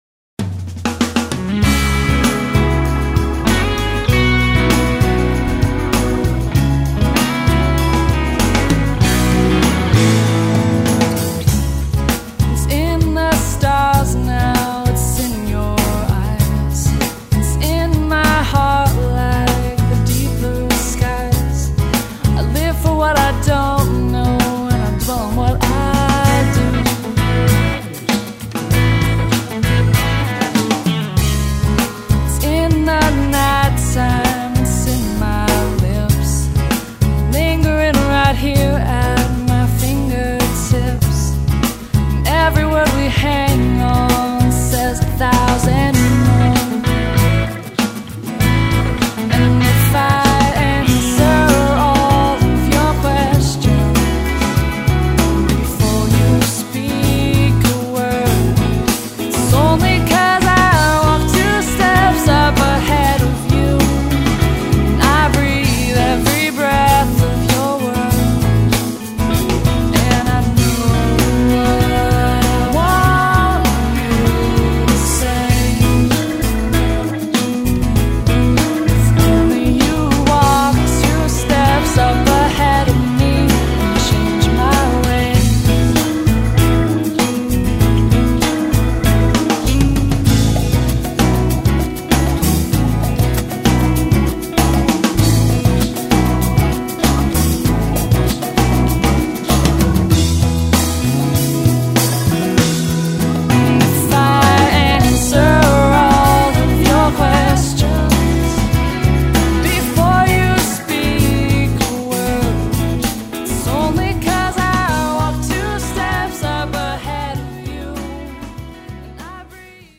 Pop Music: